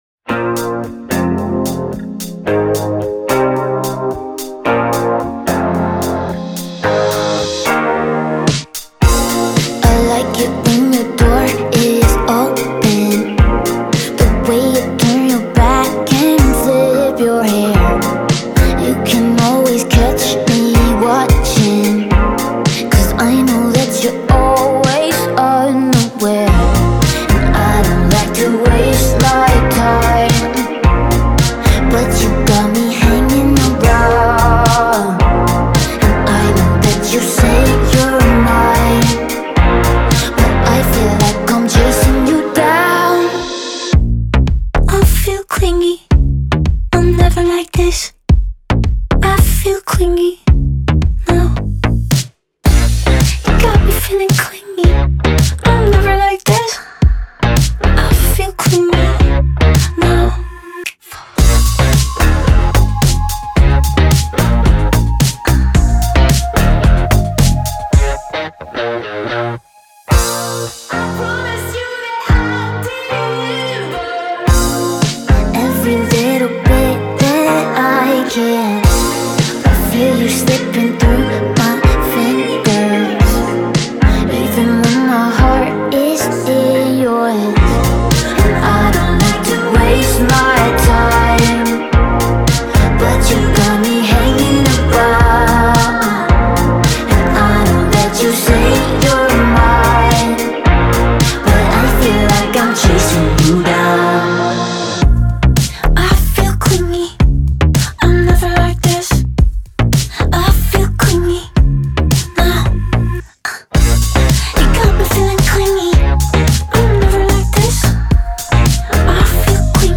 BPM110-110
Audio QualityPerfect (High Quality)
Pop/Synthwave song for StepMania, ITGmania, Project Outfox
Full Length Song (not arcade length cut)